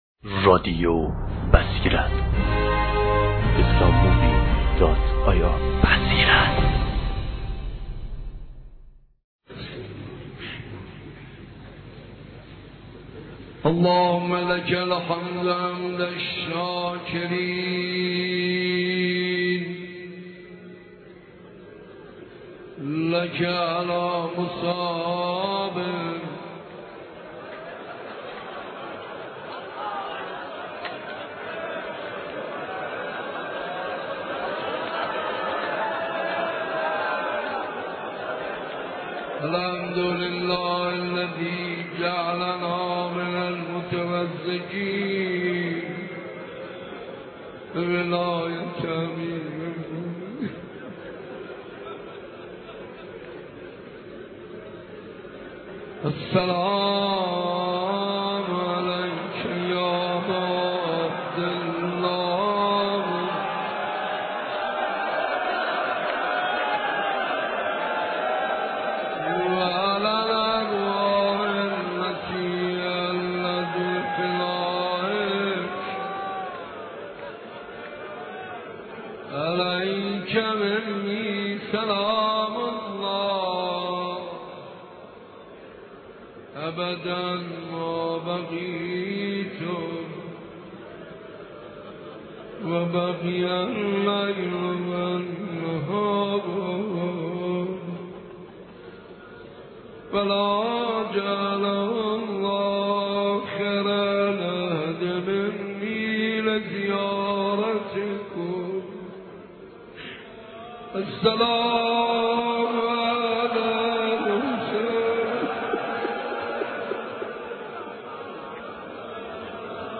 دانلود صوت شب به یادماندنی حاج منصور ارضی – روضه مسلم(ع) و… (عرفه) – شب اول محرم ۸۶ – مجله نودیها
اشتراک گذاری : رادیو بصیرت – مداحی بسم الله الرحمن الرحیم – برای برخط گوش دادن یا بارگیری به ادامه بروید.